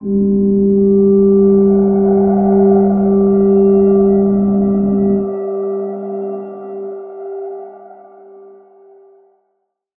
G_Crystal-G4-mf.wav